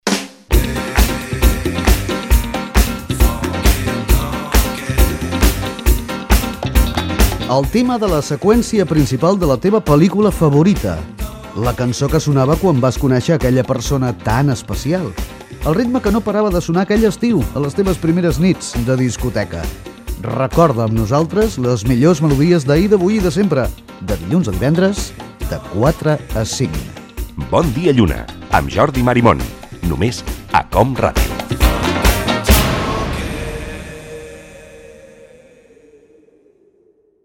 Promoció del programa
Musical